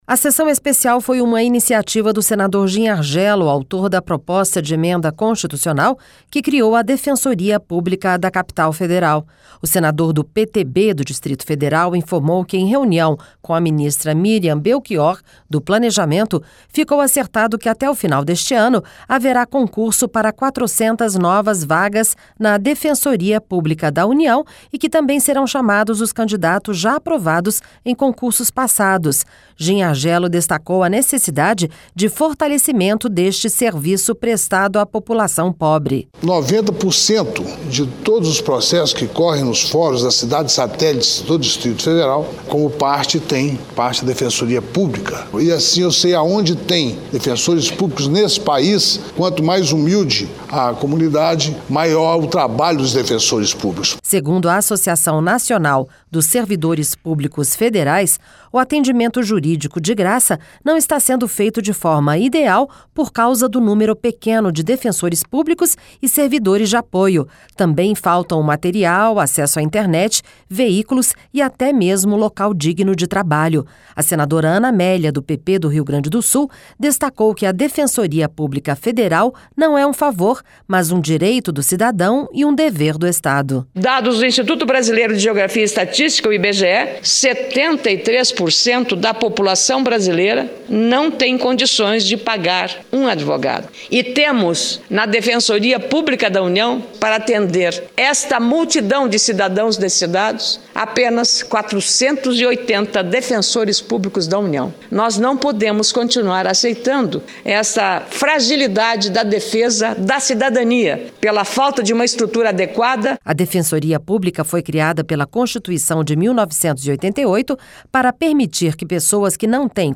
Senadora Ana Amélia